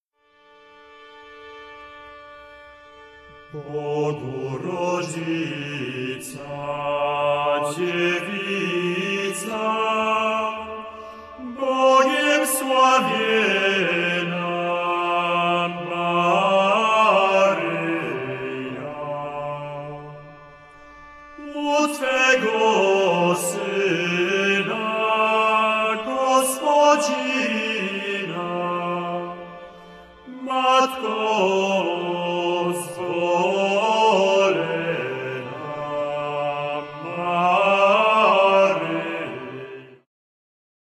lira korbowa, psałterium, bęben, viola da gamba